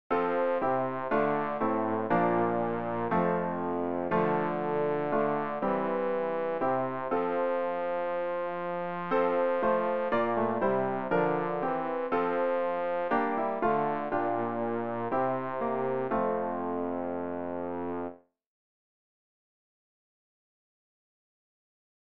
Übehilfen für das Erlernen von Liedern
rg-746-ja-ich-will-euch-tragen-bass.mp3